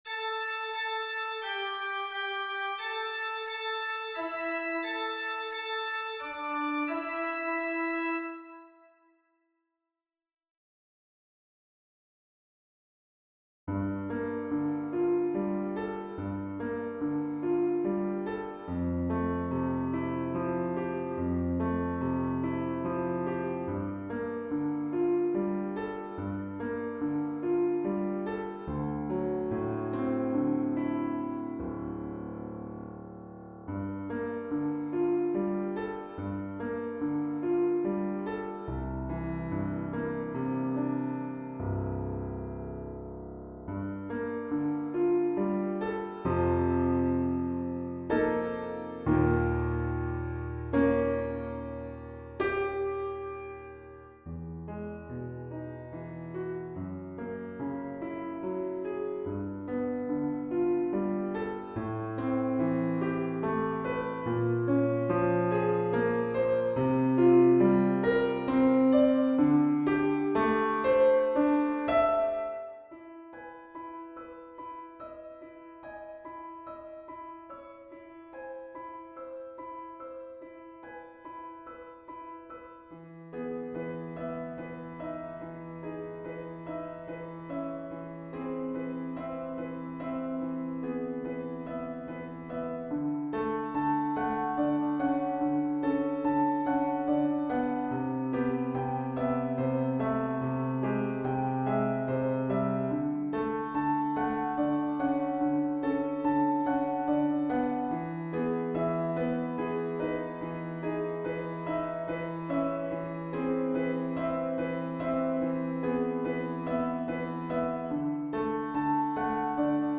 Piano (2025) / Strijkorkest (2025)